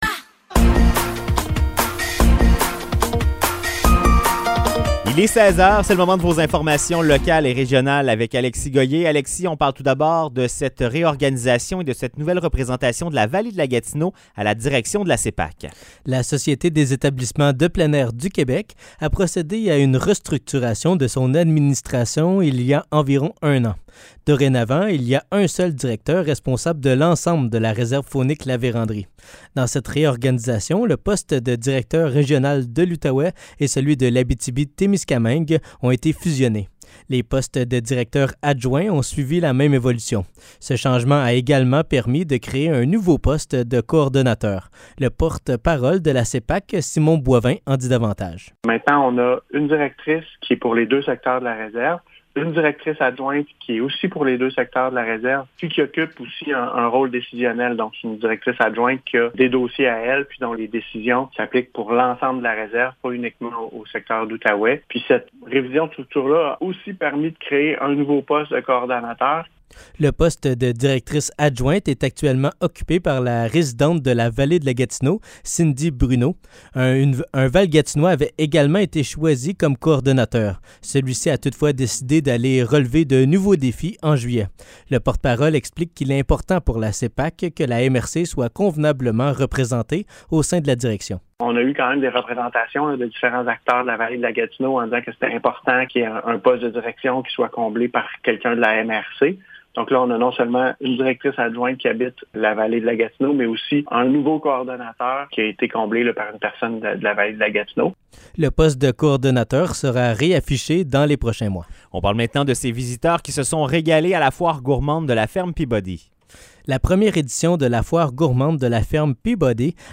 Nouvelles locales - 5 septembre 2023 - 16 h